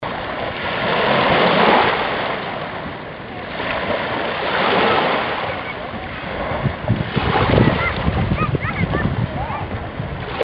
rauschen.mp3